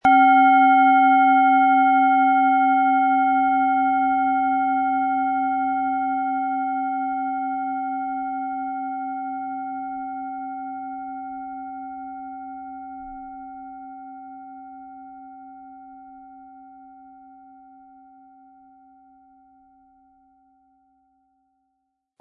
Planetenton 1
Sie sehen und hören eine von Hand gefertigt Biorhythmus Körper Klangschale.
MaterialBronze